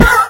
sounds / mob / horse / hit1.mp3